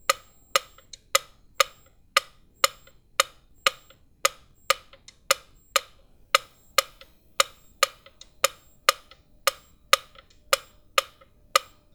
Tiempo andante en un metrónomo
andante
metrónomo